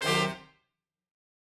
GS_HornStab-D7b2sus4.wav